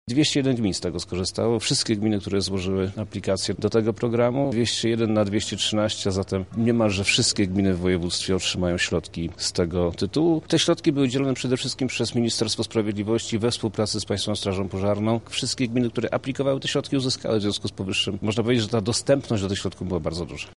To środki przeznaczone na sprzęt, który ma służyć usuwaniu skutków wypadków drogowych – mówi wojewoda lubelski Przemysław Czarnek.
-dodaje Czarnek.